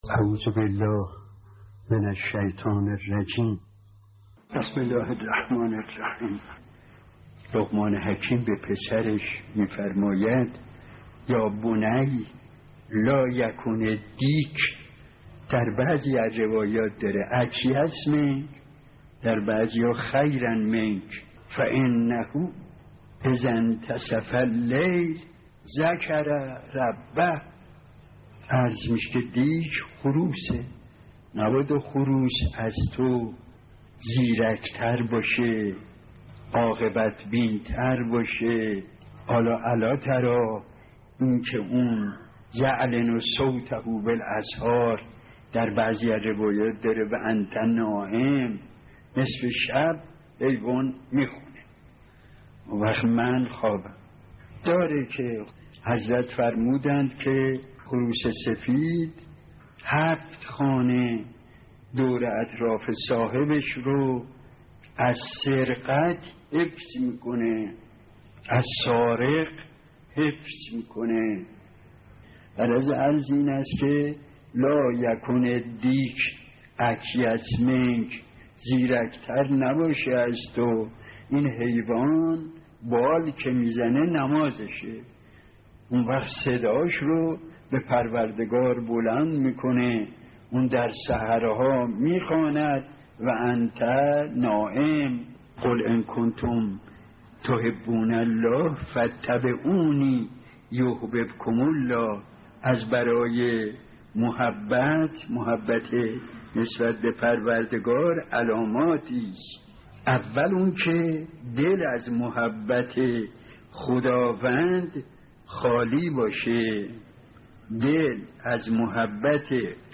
درس اخلاق | بندگی یعنی آزاد شدن از تعلقات دنیا